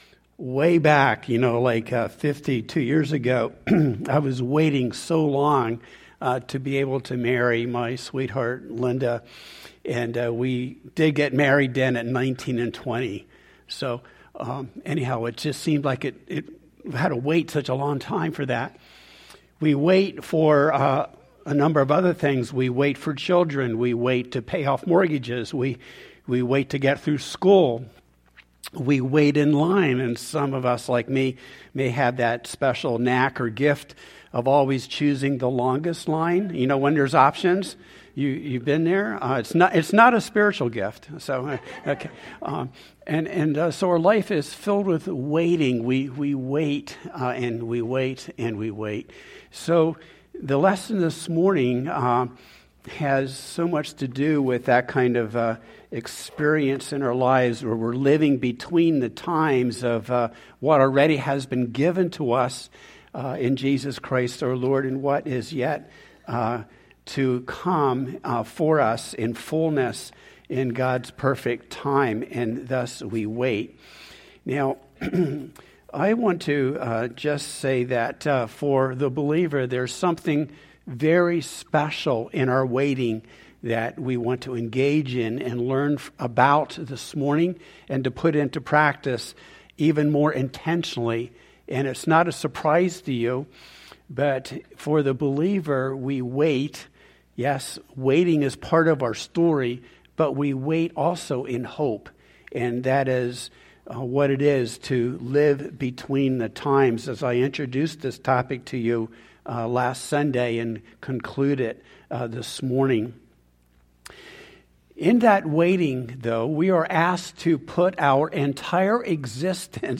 3-15-26-Sermon-Living-Between-The-Time-Part-B.mp3